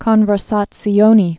(kŏnvər-sätsē-ōnē, kōnvĕr-sä-tsyōnĕ)